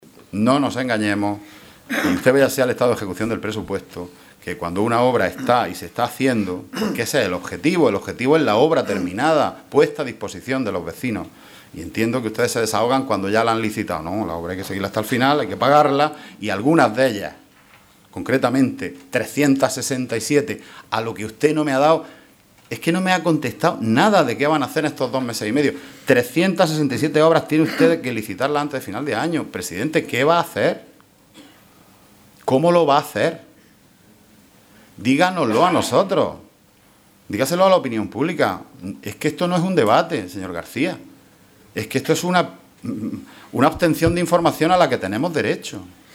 Así lo ha expresado esta mañana el portavoz socialista, Juan Antonio Lorenzo, en el Pleno Extraordinario celebrado –a petición del PSOE- para conocer de primera mano la situación en la que se encuentran los diferentes planes